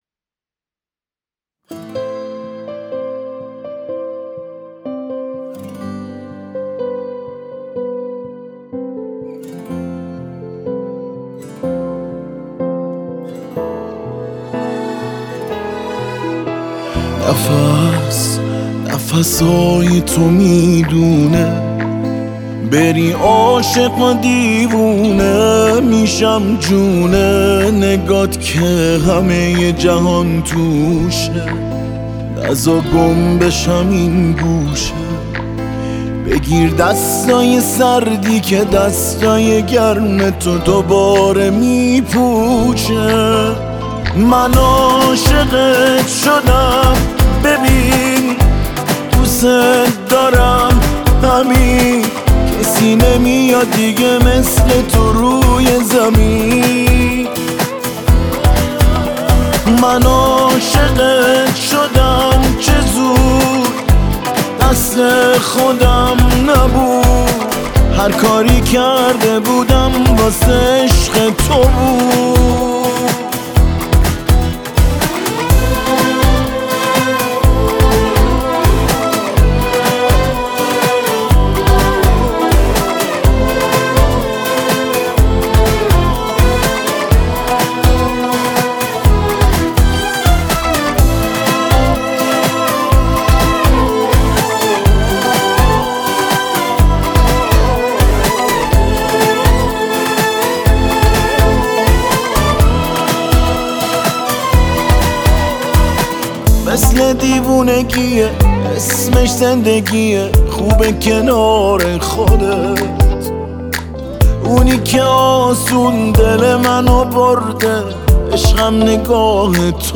نوازنده ویولن
نوازنده گیتار